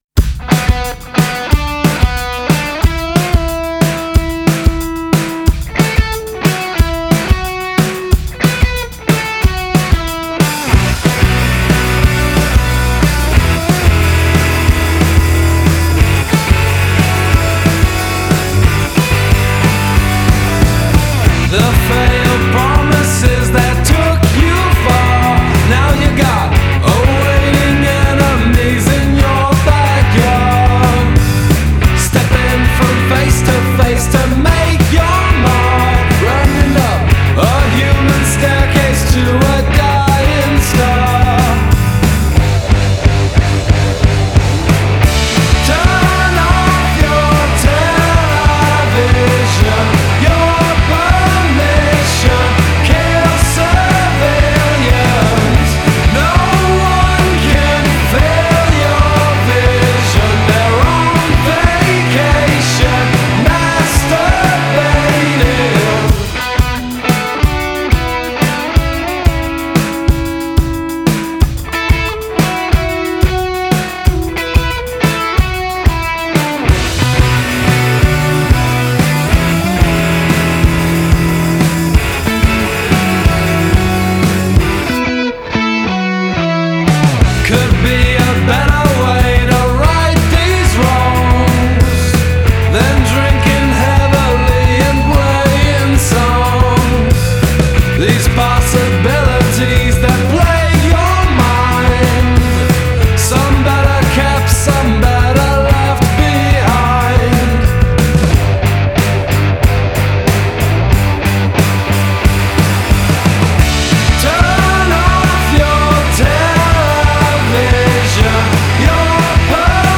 provide rock club sing-along vocals